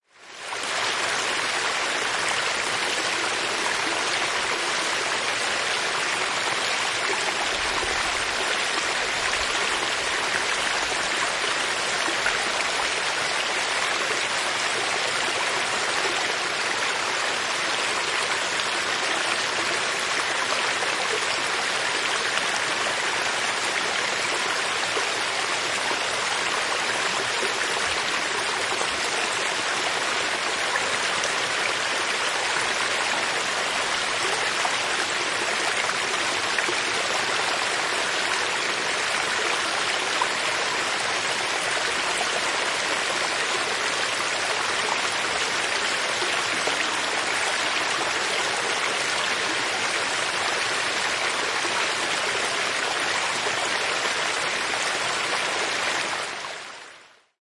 Eläimet Hyönteiset Animals » Mehiläinen, tarhamehiläinen, parvi / Bees, honeybees, a swarm of bees buzzing among the flowers (A
描述：Kesymehiläiset, mehiläiset, surisevat, lentelevät ja keräävät mettä kukissa, mehiläisparvi, kesä.
标签： Luonto Nature Honeybee Buzz Yleisradio Yle Tehosteet Insects Bee Finland Suomi FinnishBroadcastingCompany FieldRecording Soundfx Summer
声道立体声